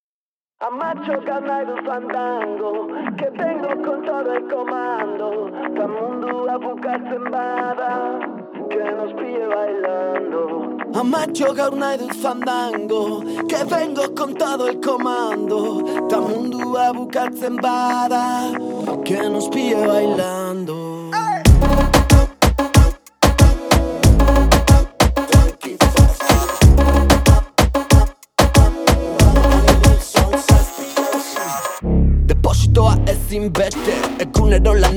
Alternative Electronic
Жанр: Альтернатива / Электроника